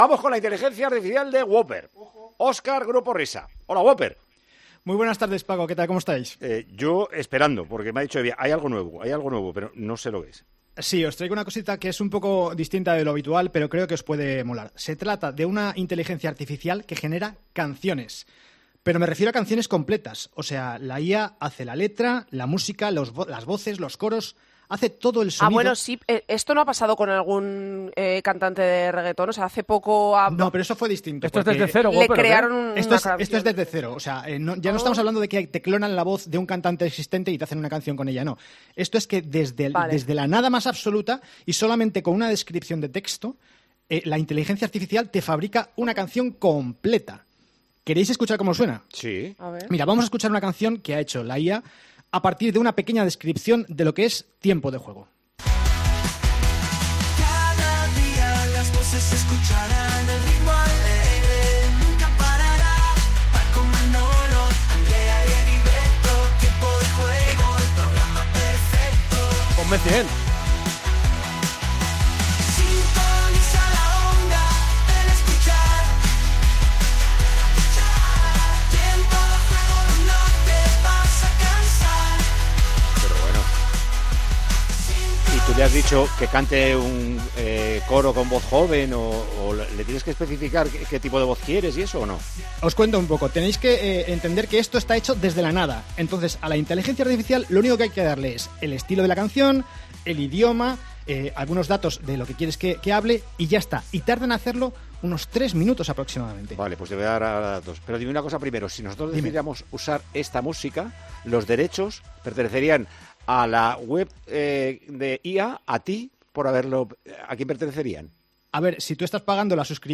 En el siguiente audio podrás escuchar íntegra la sección con todas esas canciones.